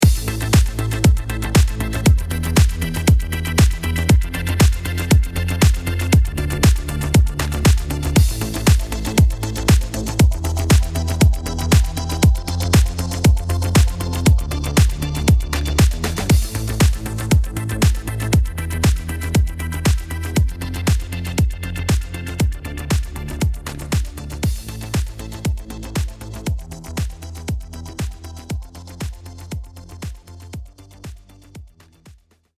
Here’s what the track sounds like with no compression applied to the drums: